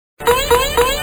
SFX跳飞音效下载
SFX音效